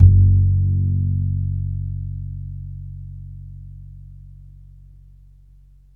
DBL BASS G#1.wav